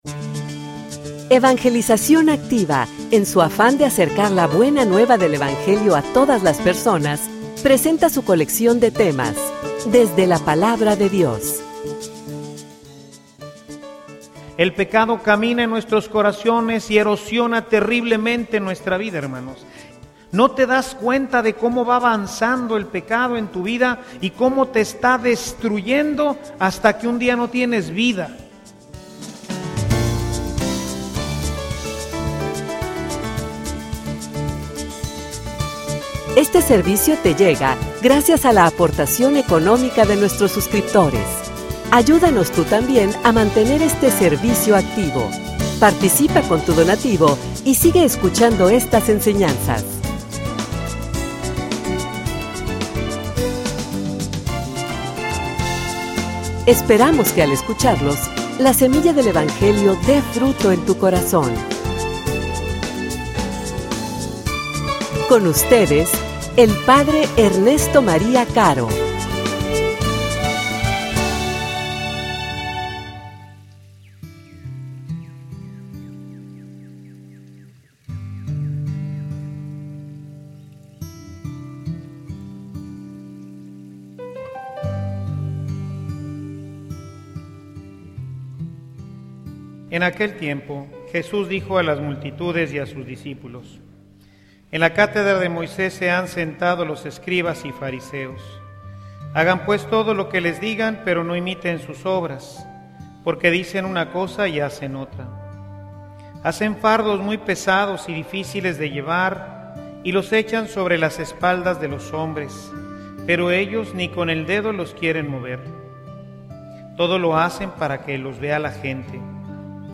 homilia_Congruencia.mp3